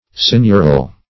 Meaning of seignioral. seignioral synonyms, pronunciation, spelling and more from Free Dictionary.
Search Result for " seignioral" : The Collaborative International Dictionary of English v.0.48: Seignioral \Seign"ior*al\, a. Of or pertaining to a seignior; seigneurial.